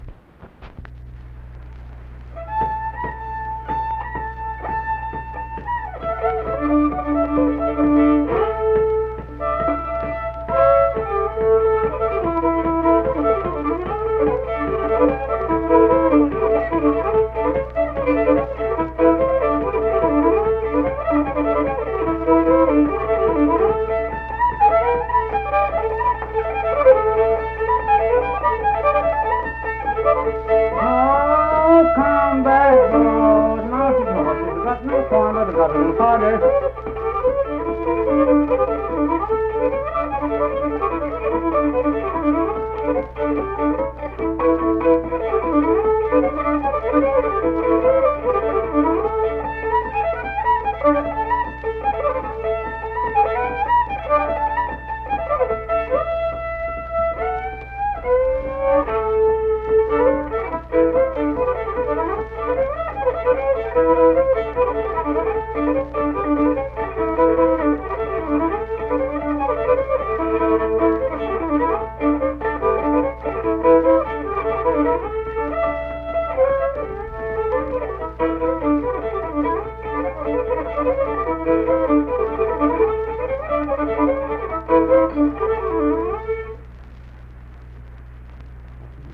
Unaccompanied vocal and fiddle music
Instrumental Music
Voice (sung), Fiddle
Kirk (W. Va.), Mingo County (W. Va.)